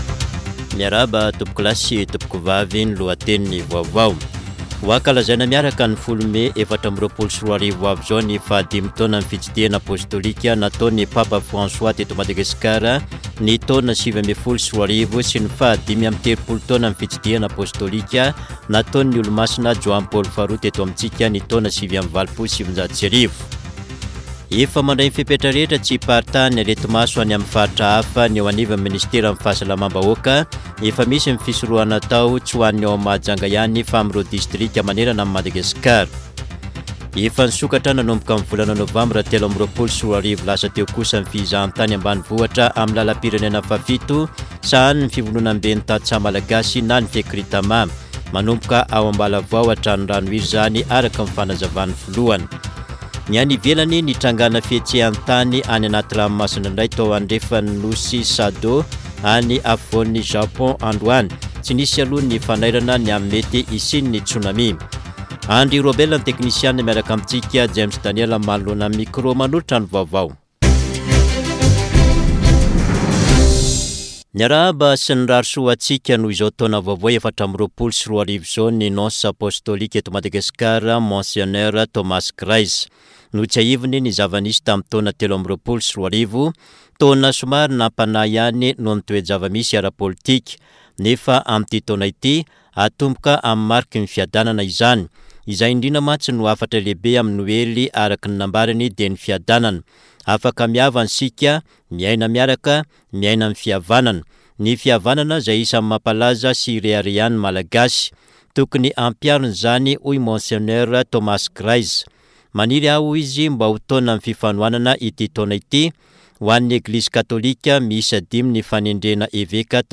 [Vaovao hariva] Talata 9 janoary 2024